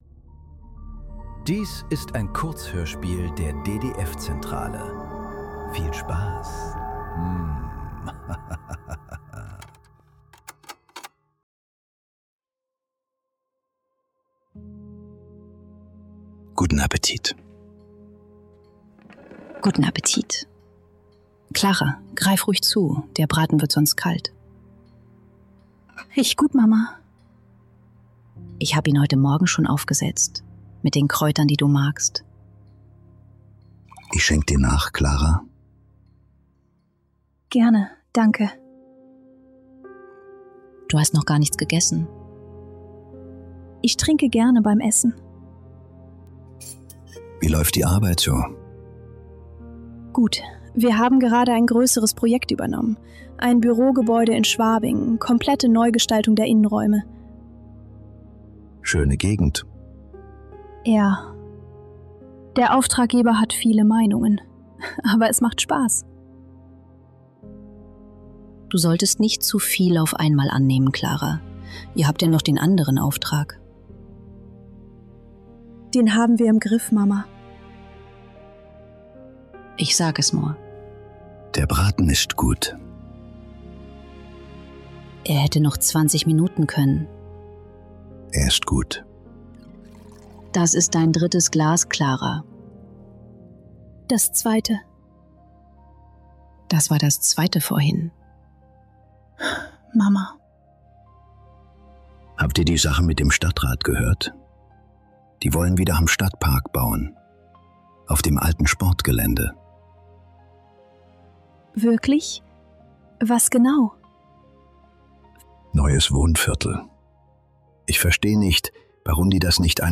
Kurzhörspiele.